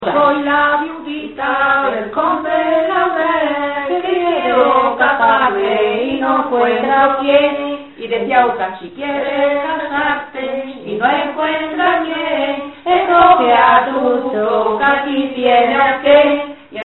Materia / geográfico / evento: Canciones de corro Icono con lupa
Ventas de Huelma (Granada) Icono con lupa
Secciones - Biblioteca de Voces - Cultura oral